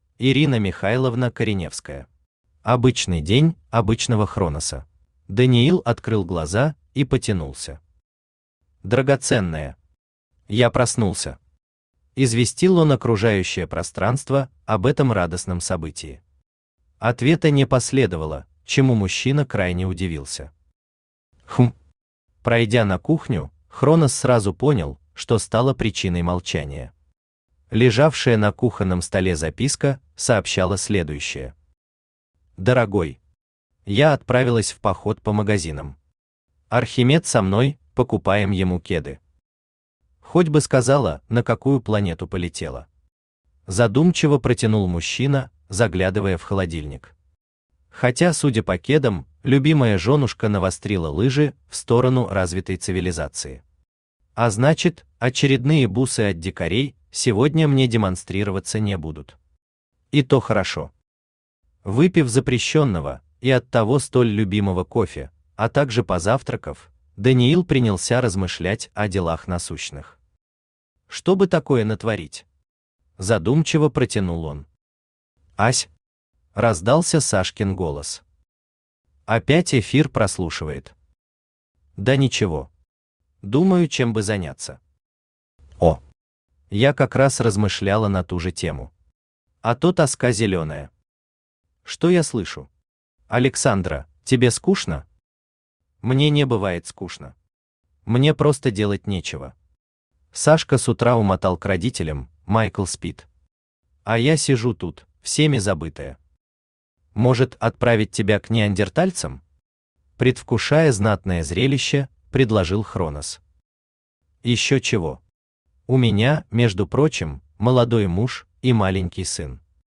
Аудиокнига Обычный день обычного Хроноса | Библиотека аудиокниг
Aудиокнига Обычный день обычного Хроноса Автор Ирина Михайловна Кореневская Читает аудиокнигу Авточтец ЛитРес.